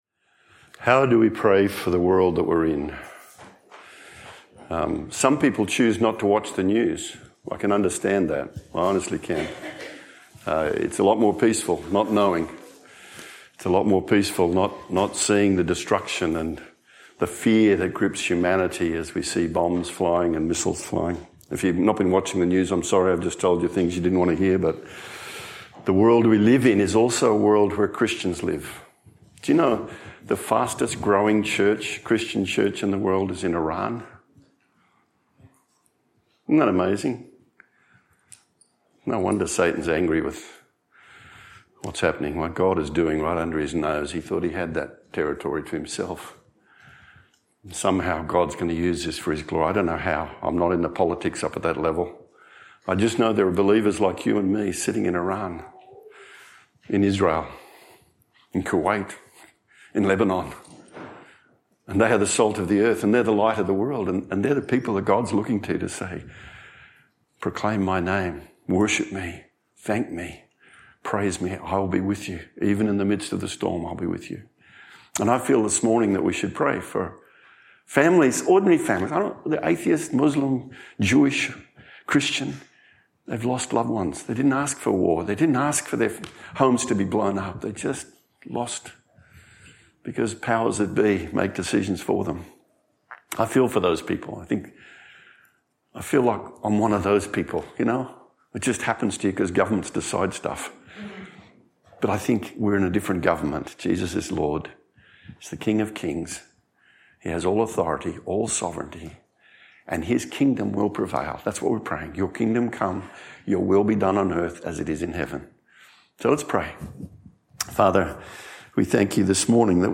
Baptism
A Baptism service today